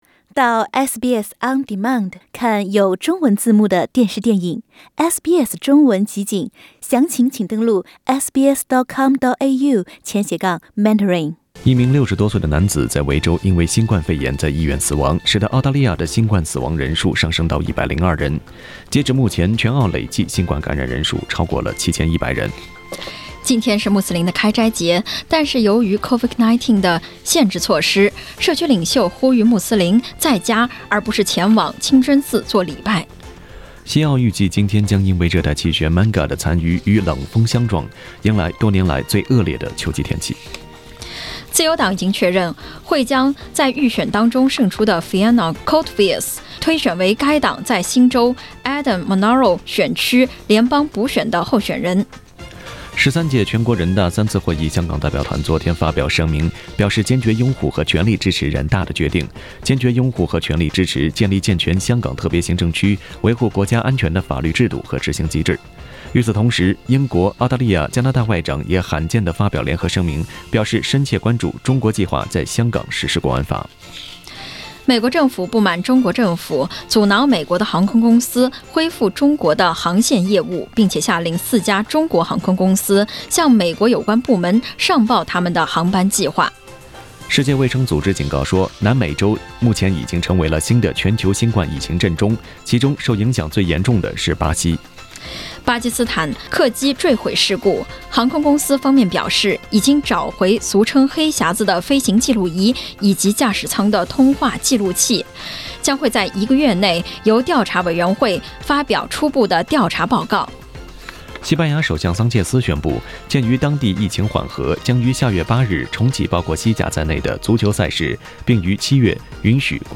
SBS早新闻（5月24日）